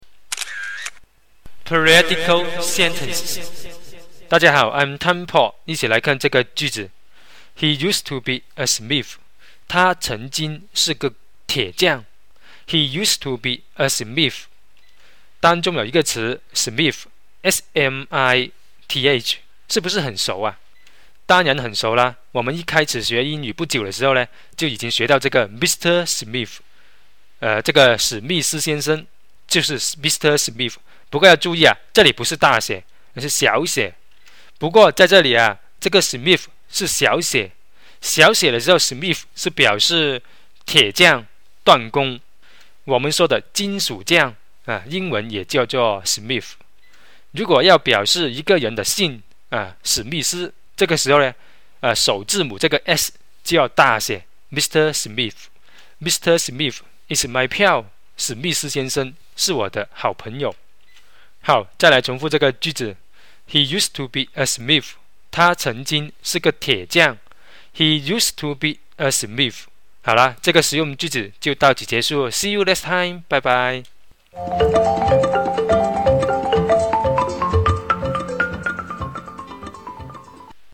dissect是个动词，一般表示解剖，dissect也有仔细分析和剖析的意思，但表示这个意思时要注意它的发音，当中的i要发短音。